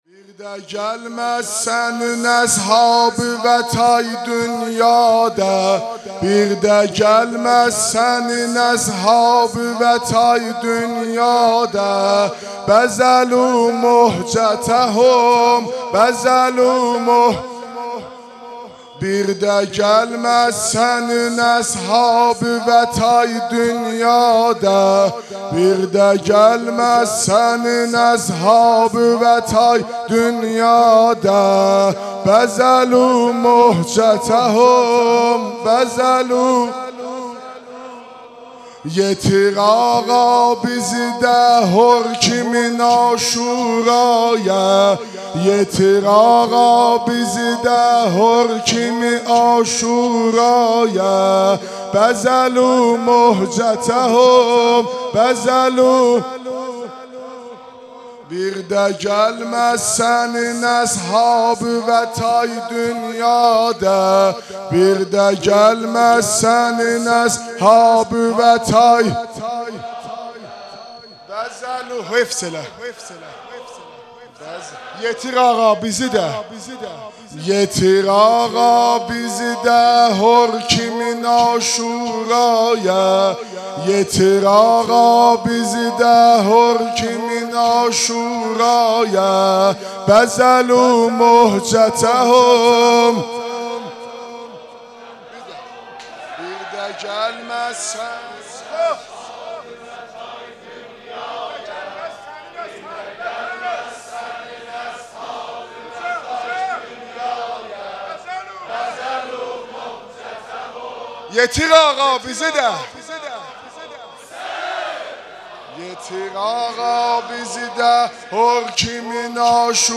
مداحی شب چهارم محرم 1399 با نوای حاج مهدی رسولی
آخرين خبر/ مداحي شب چهارم محرم 1399 با نواي حاج مهدي رسولي، هيئت ثارالله زنجان
1- روضه ي اصحاب